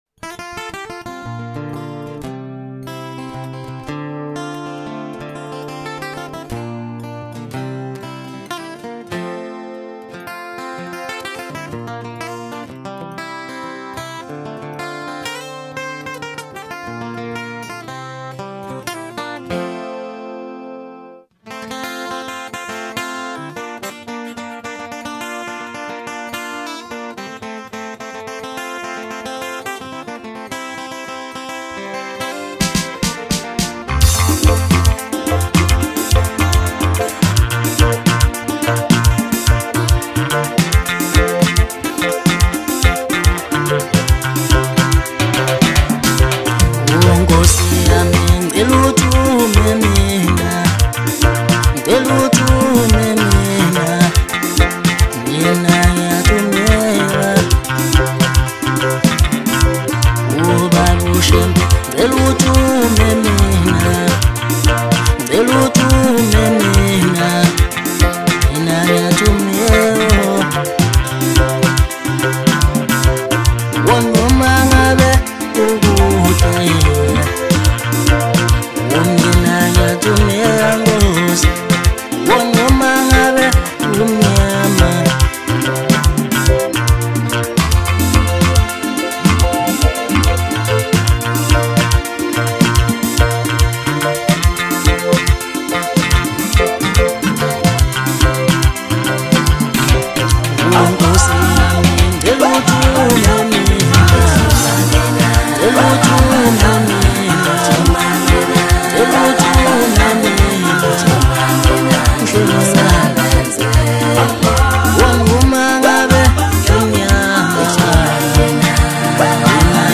soothing atmospheres